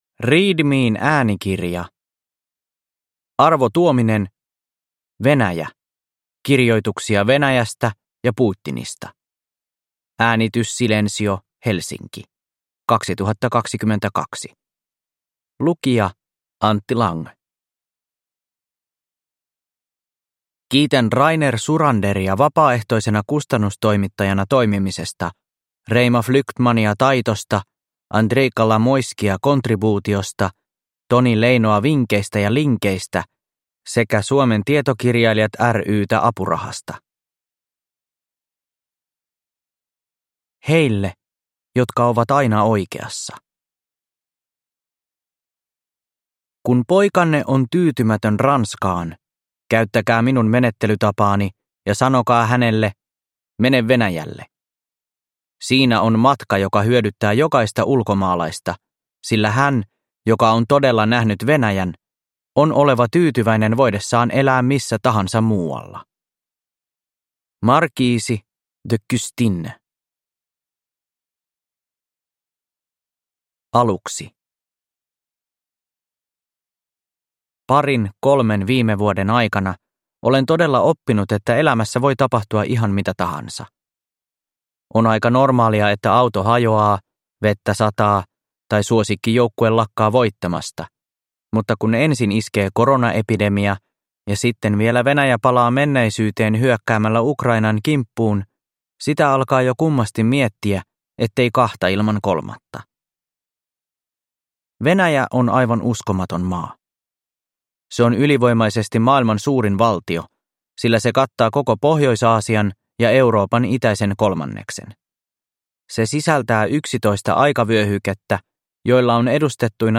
Venäjä (ljudbok) av Arvo Tuominen